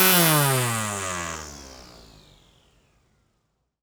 STRIMMER_Off_mono.wav